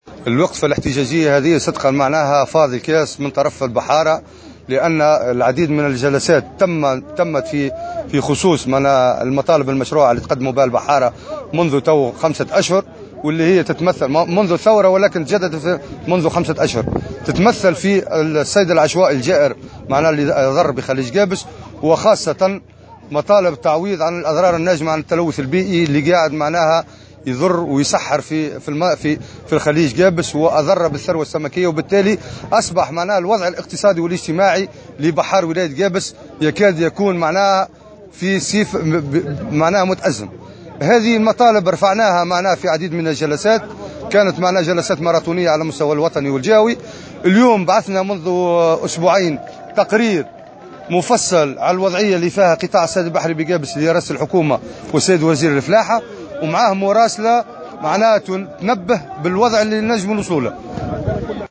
في تصريح للجوهرة أف ام